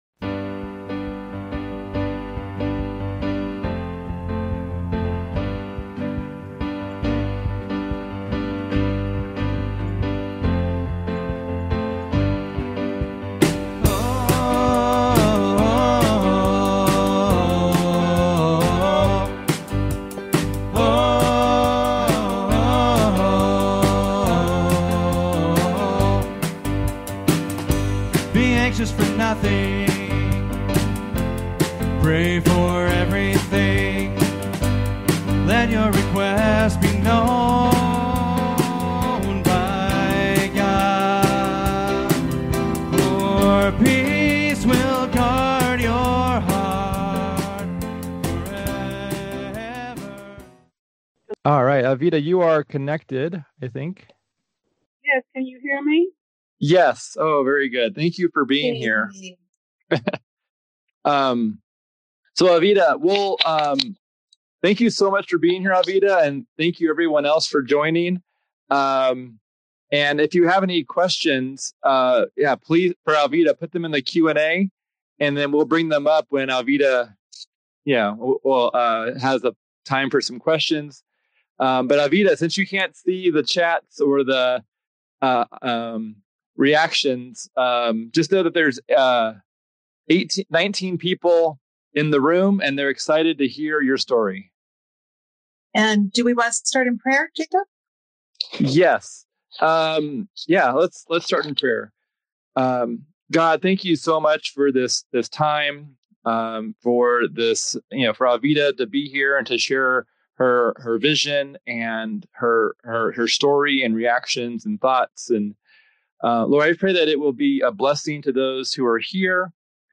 In this conversational webinar